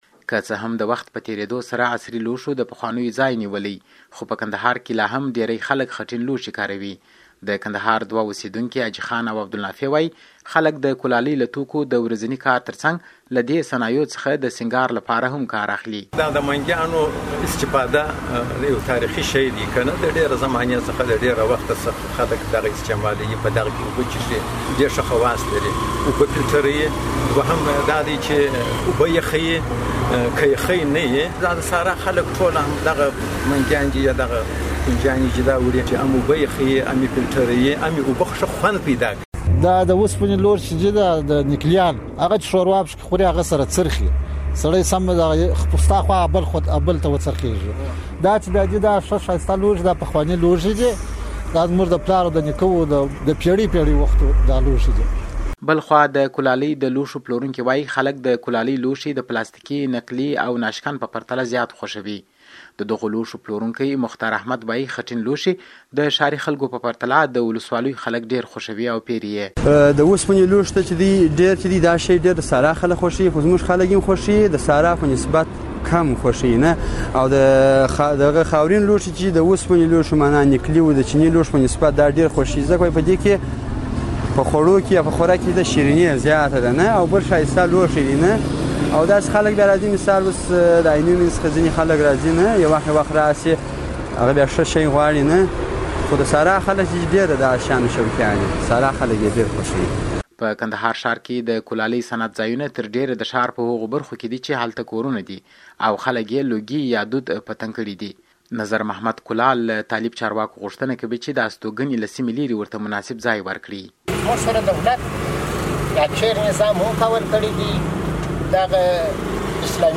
د کندهار راپور